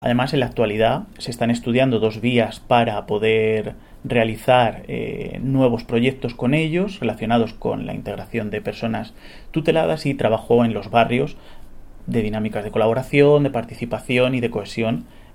Declaraciones del concejal Miguel Óscar Aparicio 2